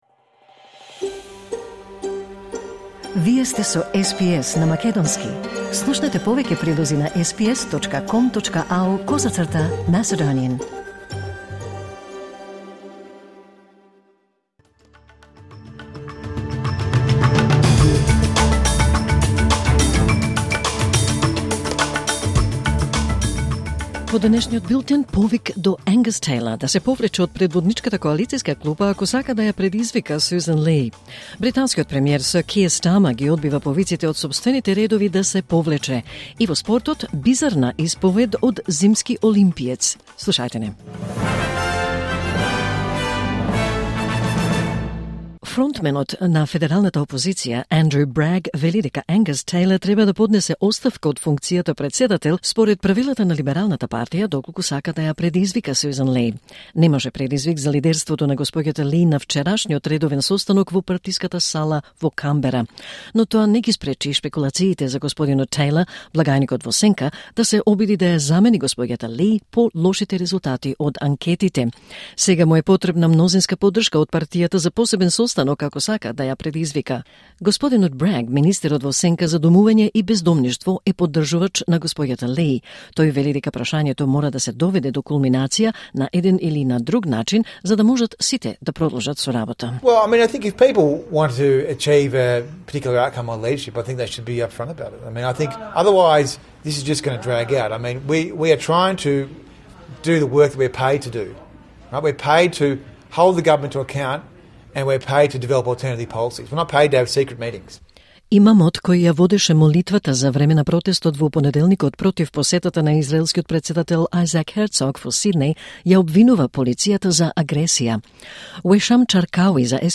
Вести на СБС на македонски 11 февруари 2026